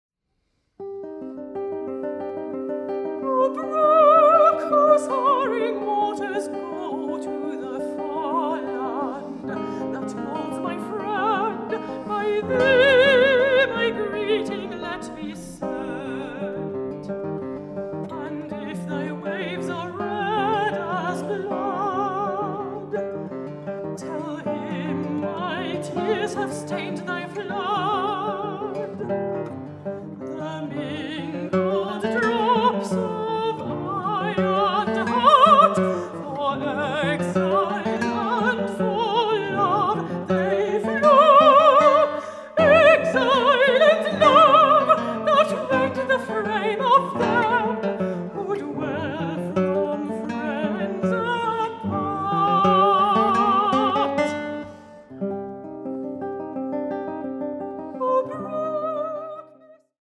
guitar
mezzo-soprano